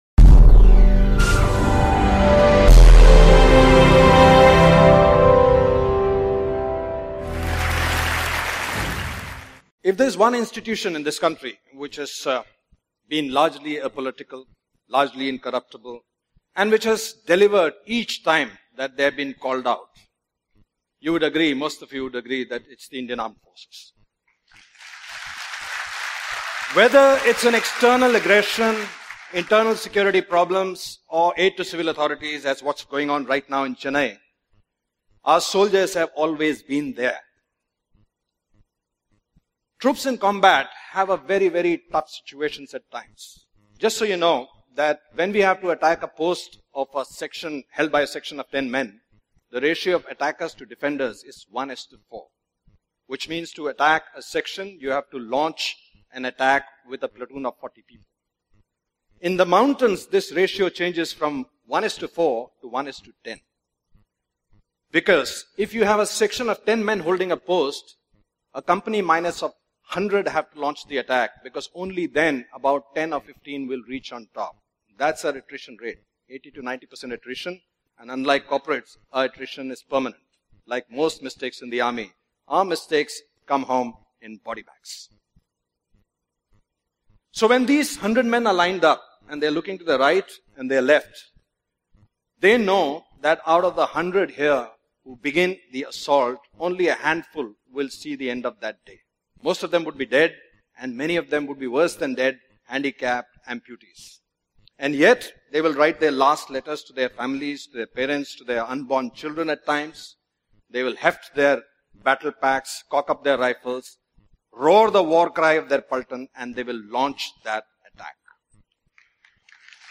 A Great Lecture on Indian Army